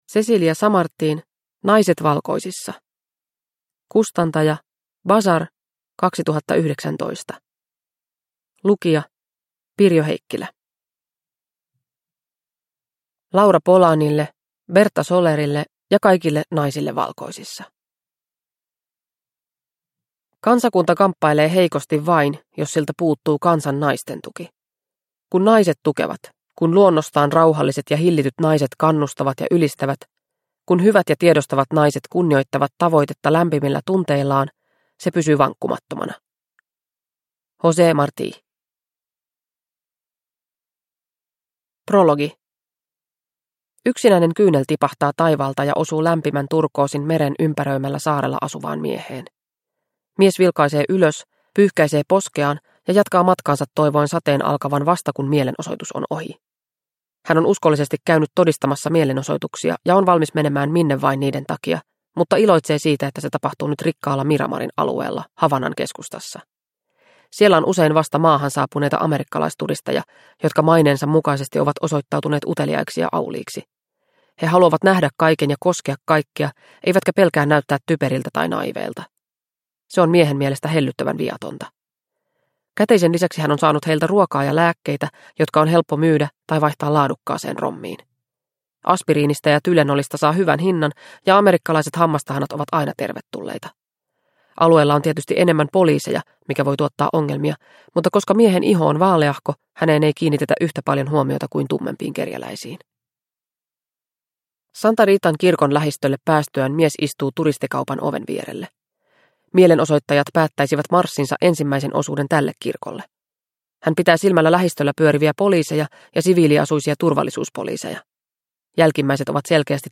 Naiset valkoisissa – Ljudbok – Laddas ner